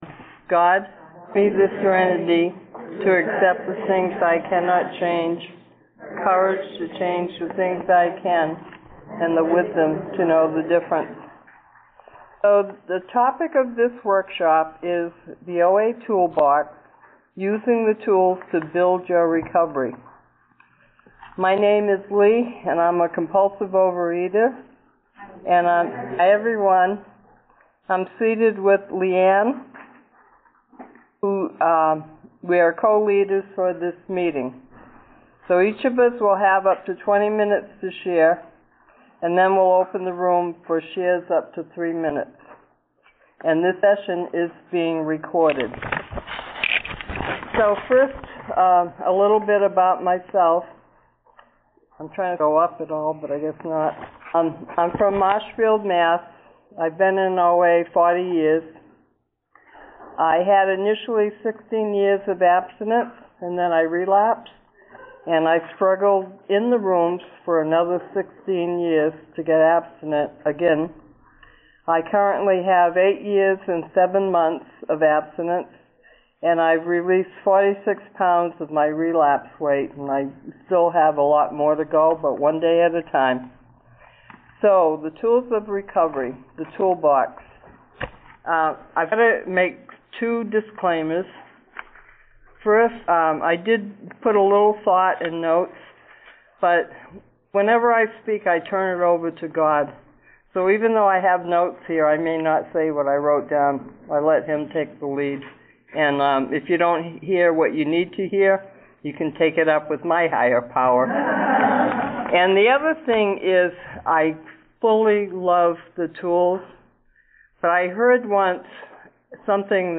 A workshop given at the 2024 OA Region 6 convention, held in October in Nashua, NH, US.